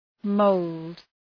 Shkrimi fonetik {məʋld}